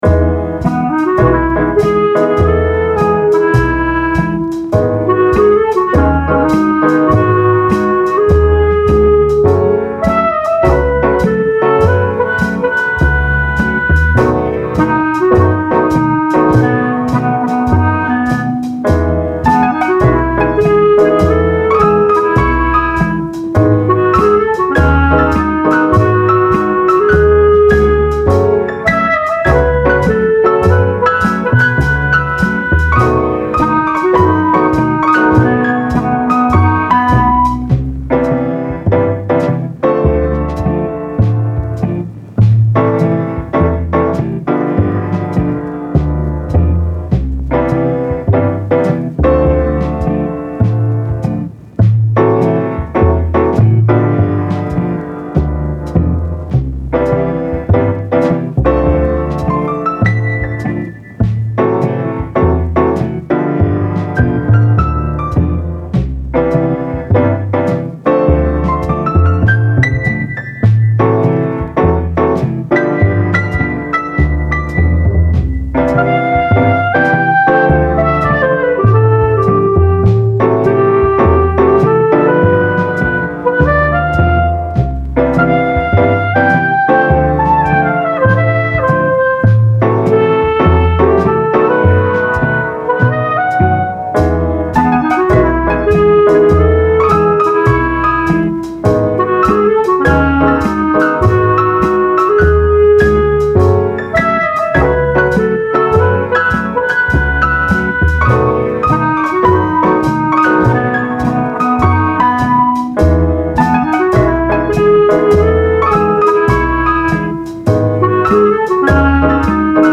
Style Style Jazz, Oldies
Mood Mood Relaxed
Featured Featured Bass, Clarinet, Drums +2 more
BPM BPM 102